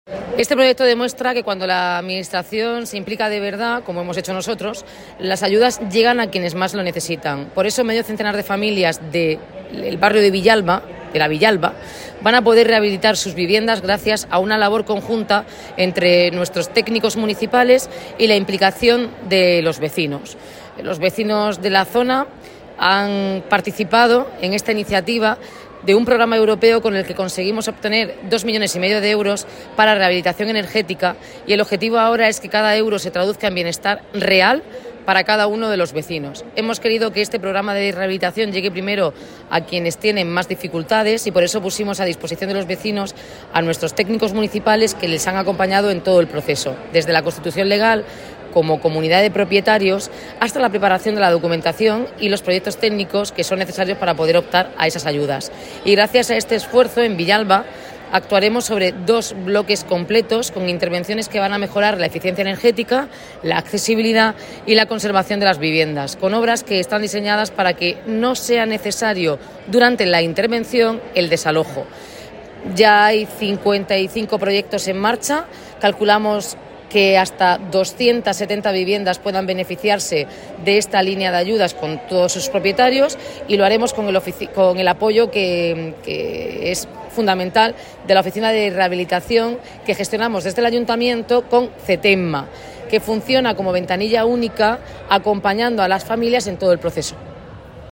Enlace a Declaraciones de la alcaldesa, Noelia Arroyo, sobre rehabilitación de viviendas en Villalba